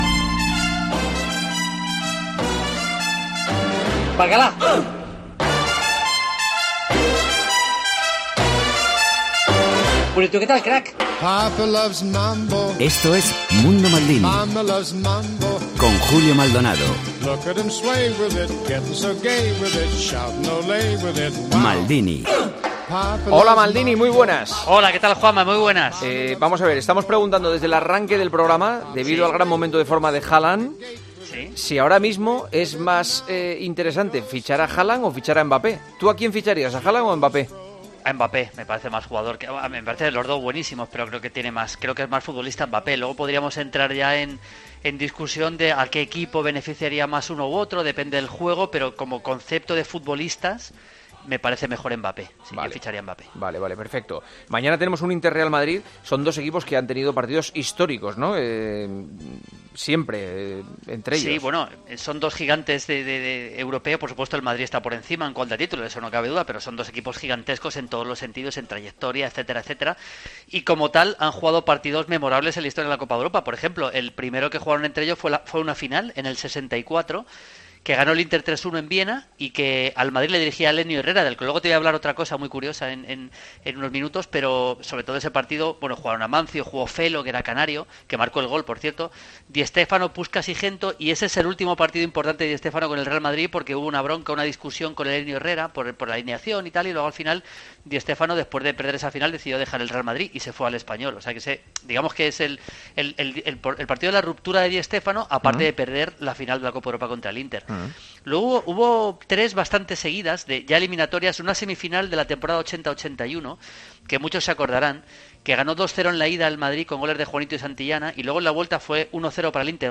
Maldini responde a los oyentes.